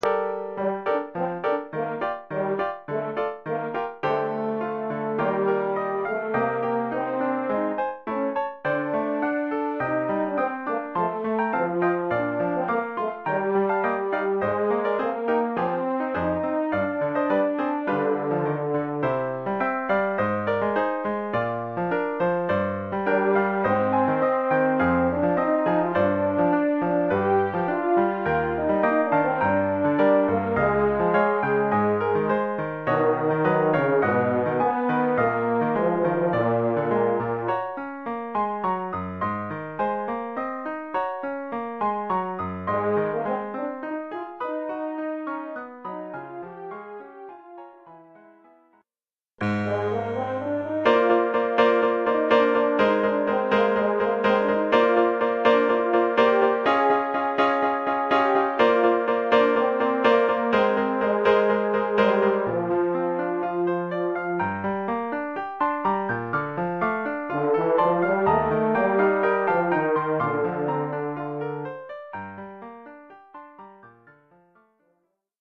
Oeuvre pour cor d'harmonie et piano.
(fa ou mib) et piano.